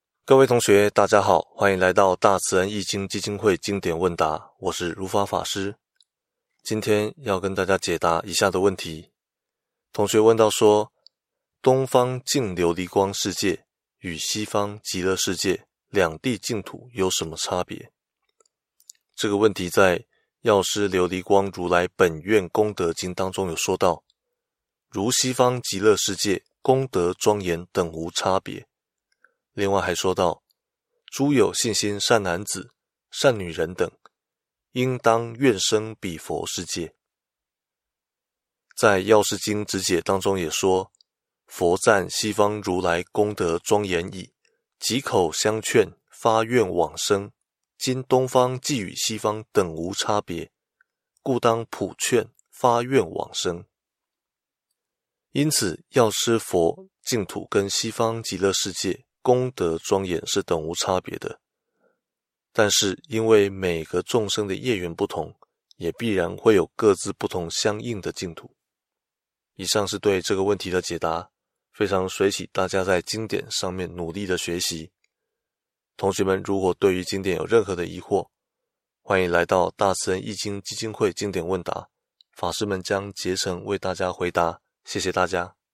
解答法師